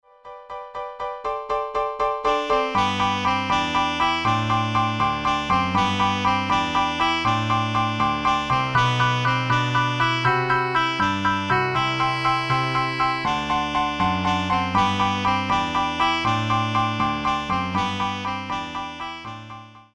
Sample from the Rehearsal MP3